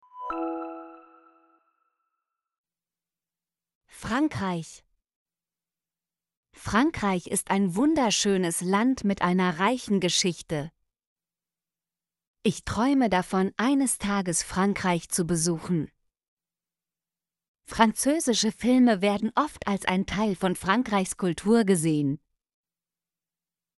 frankreich - Example Sentences & Pronunciation, German Frequency List